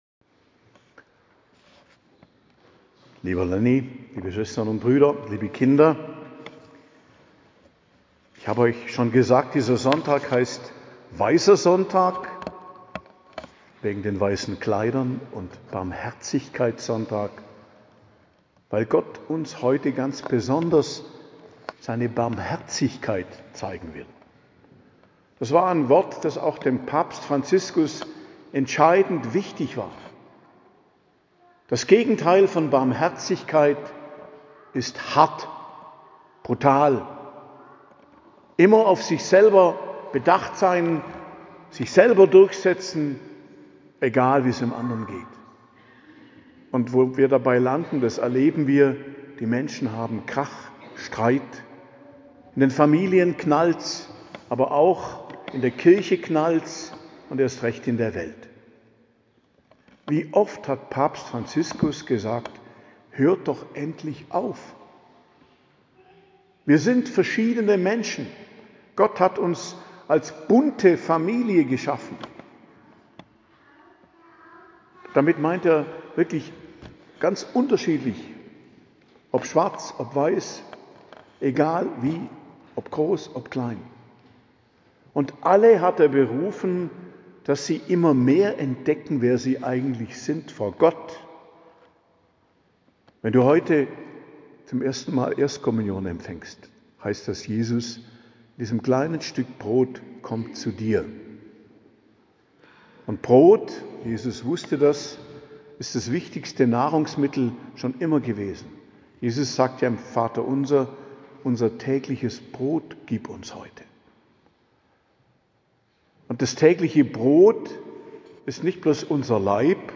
Predigt zum 2. Sonntag der Osterzeit, Weisser Sonntag, Barmherzigkeitssonntag, 27.04.2025 ~ Geistliches Zentrum Kloster Heiligkreuztal Podcast